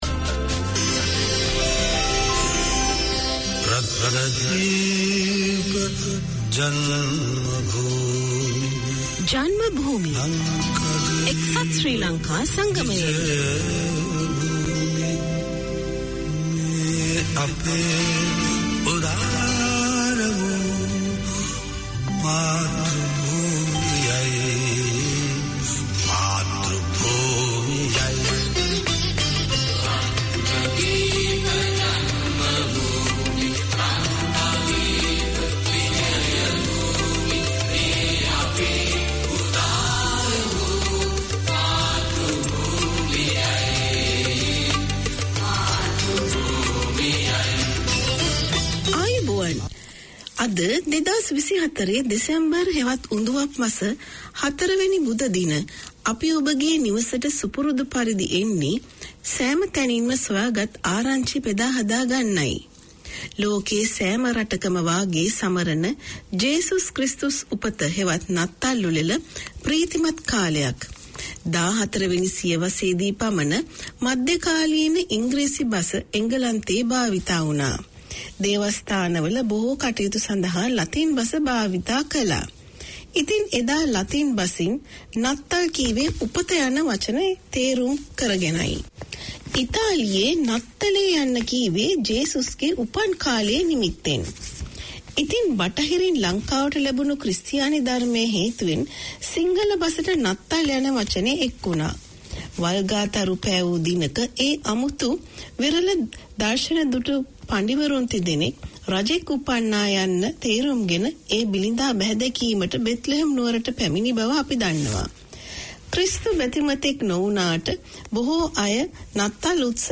For 65 minutes weekly Janma Bhoomi aims to keep Singhalese in touch with their motherland and educate the younger generation born here about the history and nature of Sri Lanka. There are historical stories and events, news and current affairs, poetry, prose and drama, festival celebrations, all wrapped around with both old and modern music.